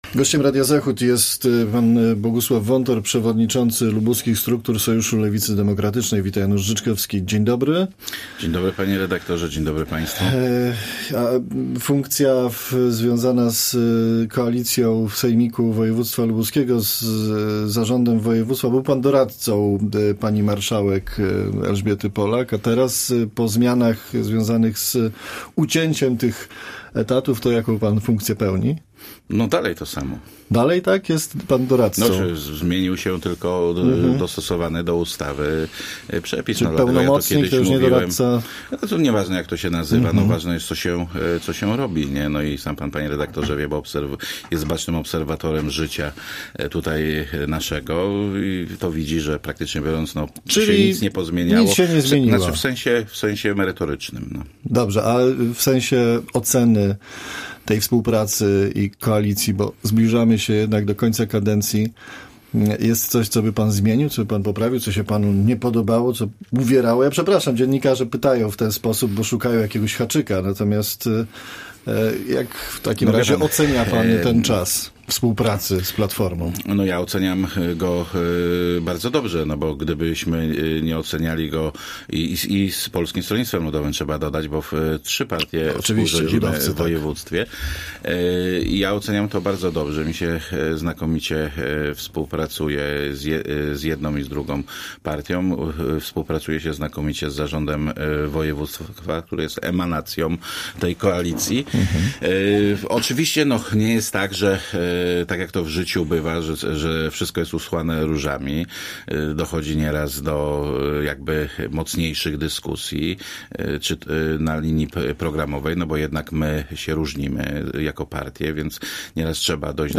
Z liderem lubuskiego Sojuszu Lewicy Demokratycznej rozmawia